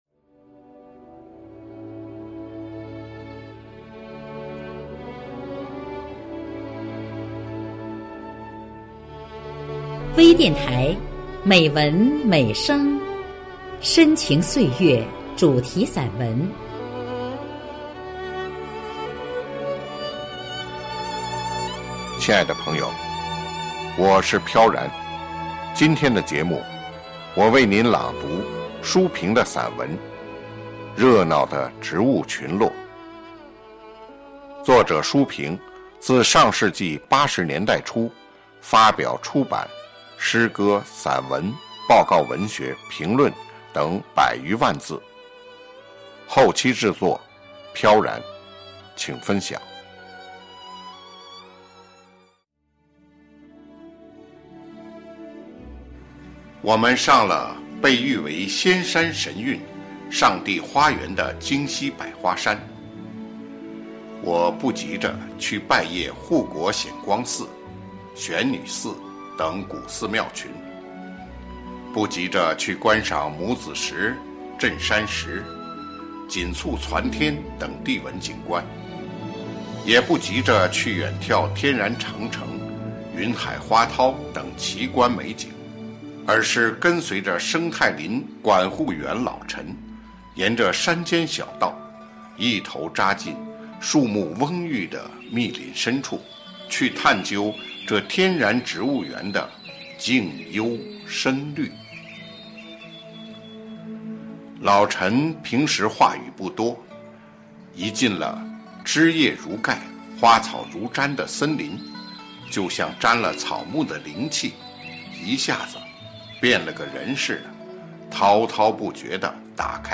热闹的植物群落——北京百花山森林游趣》朗诵